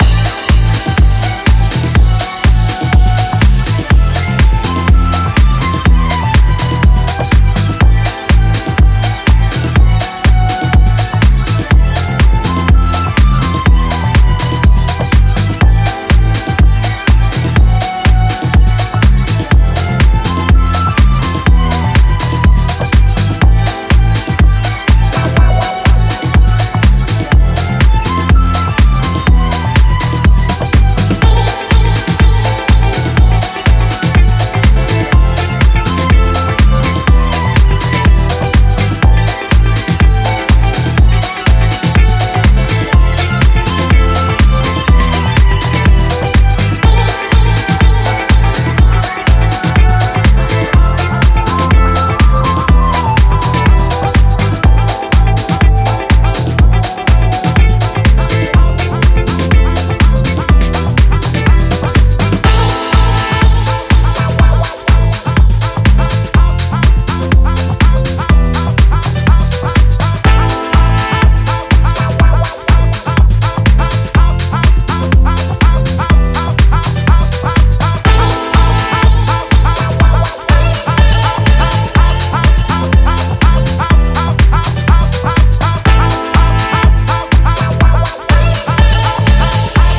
disco, funk and groove-laden